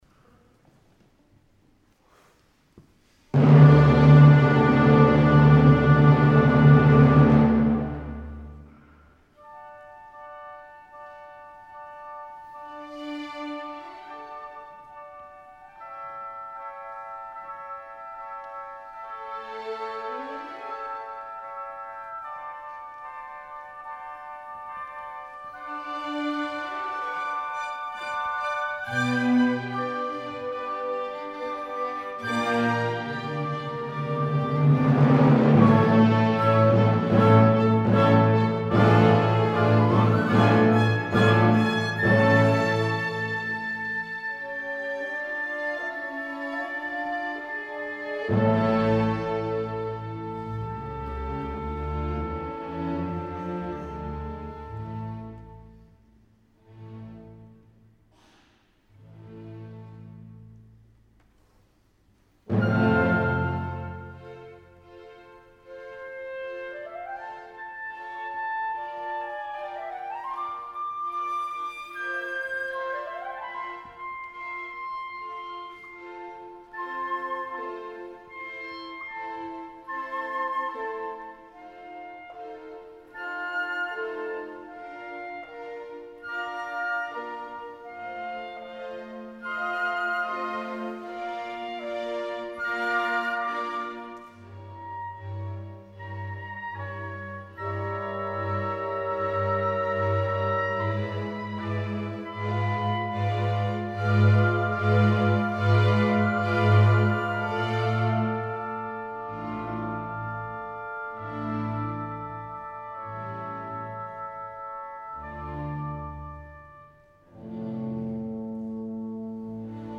F. Schubert: Sinfonía nº 3 en Re Mayor. D.200